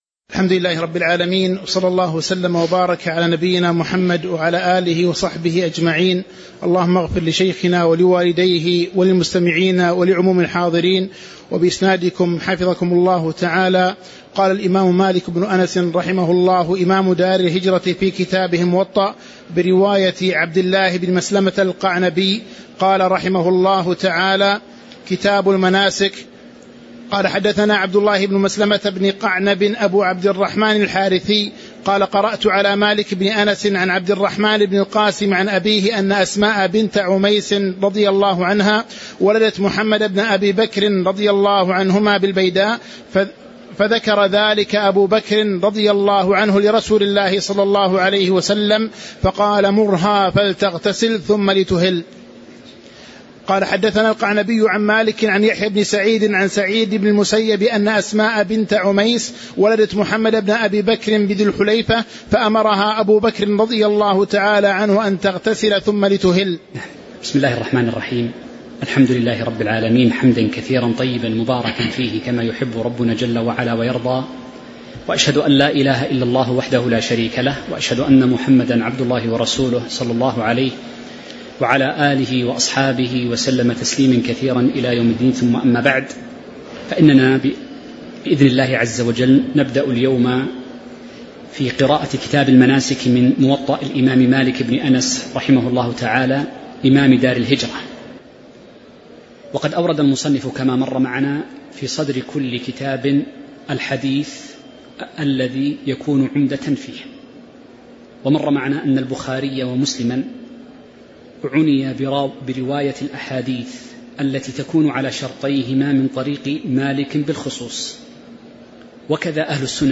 تاريخ النشر ٢٥ ذو القعدة ١٤٤٦ هـ المكان: المسجد النبوي الشيخ